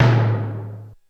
LoTom.wav